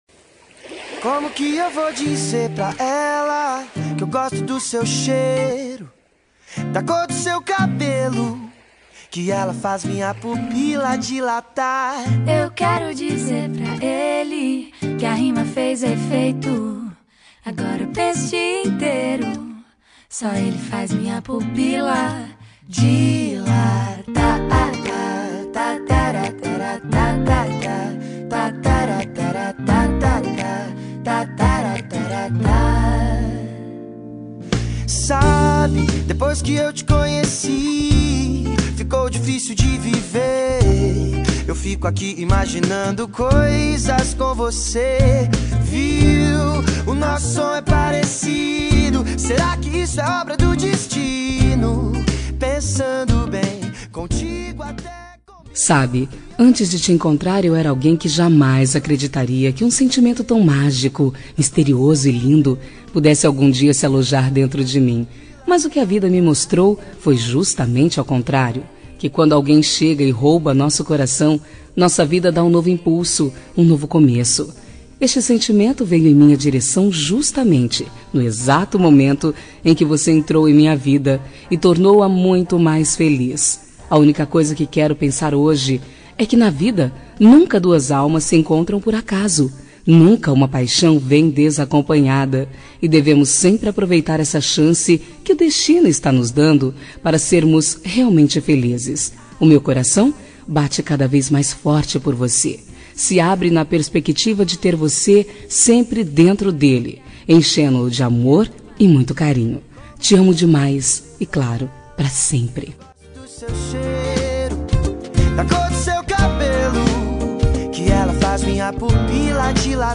Telemensagem Romântica GLS – Voz Feminina – Cód: 5477 – Linda
5477-gls-fem-rom.m4a